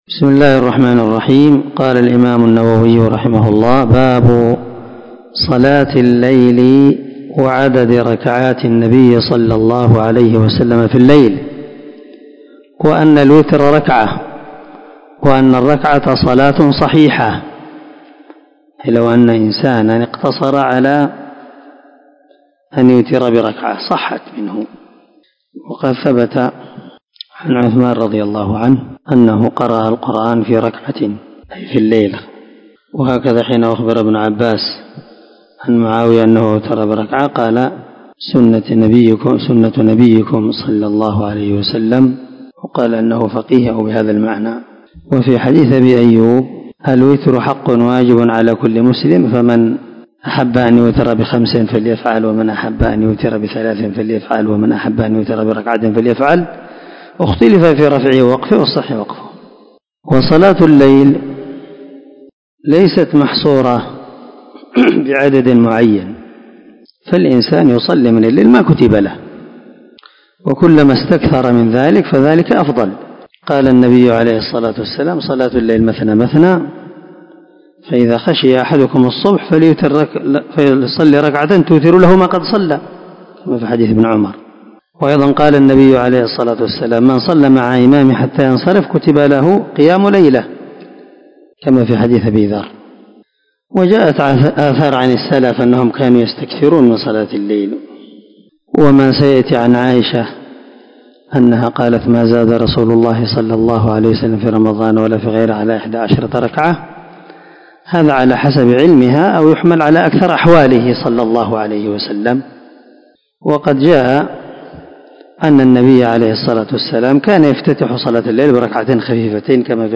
454الدرس 22 من شرح كتاب صلاة المسافر وقصرها حديث رقم ( 736 – 738 ) من صحيح مسلم
دار الحديث- المَحاوِلة- الصبيحة.